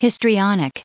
1588_histrionic.ogg